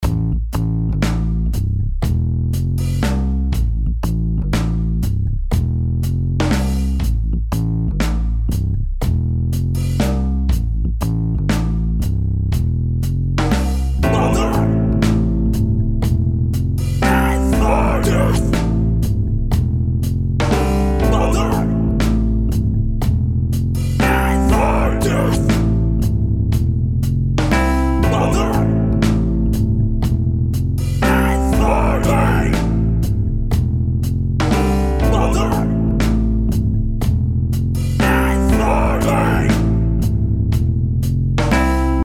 … mit Amp-Simulation, mischbaren Nebengeräuschen der Mechanik und des Pedals, Bandsättigung, Hall und den Effekten Chorus, Phaser oder Vibrato – ein weiteres Schwergewicht von Keyscape.
Die Drums stammen wieder von Toontracks EZdrummer, EZX Funk.
Der Bass stammt aus Spectrasonics Keyscape und wird vom Vintage Vibe Tine Bass Piano erzeugt.
Ein Verzerrer und eine Amp-Simulation sind mit an Bord.
Die Akkorde stammen vom oben abgebildeten Wurlitzer-Modell.